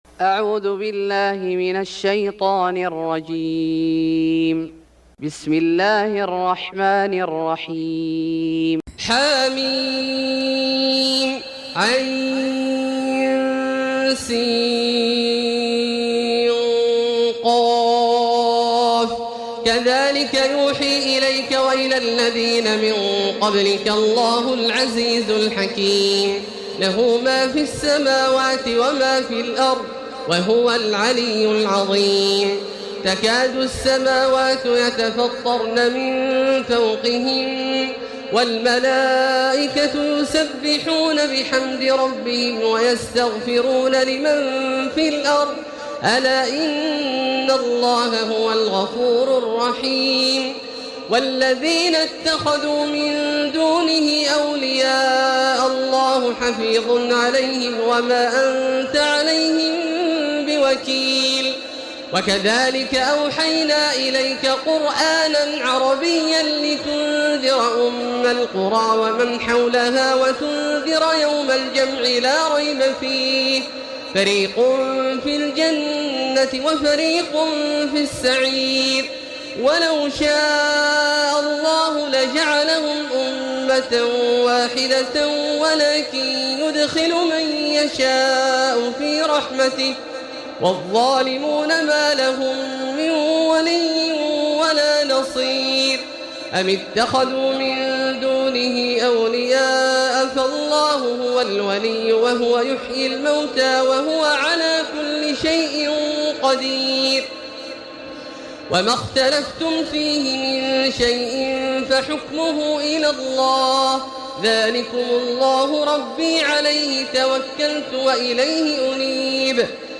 سورة الشورى Surat Ash-Shura > مصحف الشيخ عبدالله الجهني من الحرم المكي > المصحف - تلاوات الحرمين